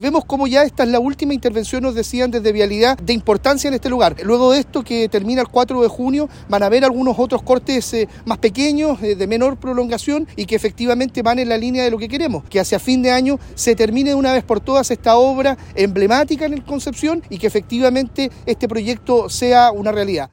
El delegado presidencial de la región del Bío Bío, Eduardo Pacheco, dijo que este será el último de los desvíos de gran magnitud y que se mantendrá hasta el 4 de junio.